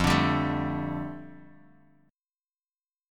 Listen to F6add9 strummed